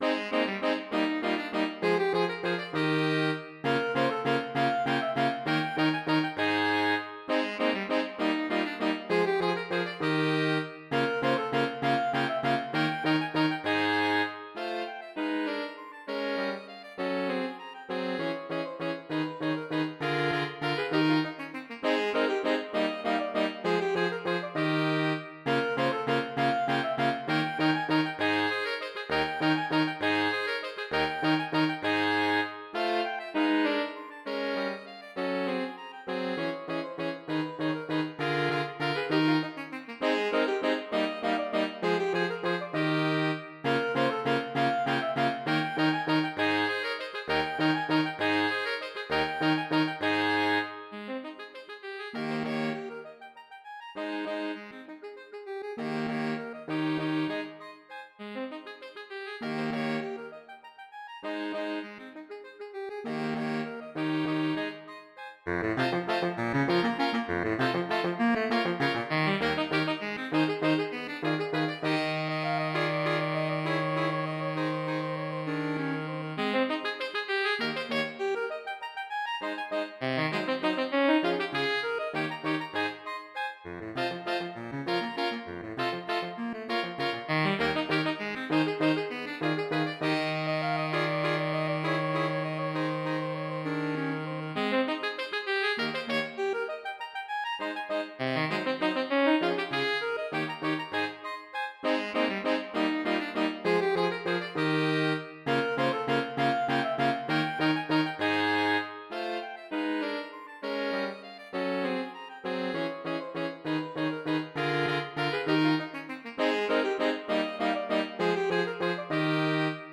Saxophone Quintets
mp3 SCORE Wind Quintet Opus 56 No 1 Menuetto [Franz Danzi] 4 SAATB or SATTB Lively Minuet.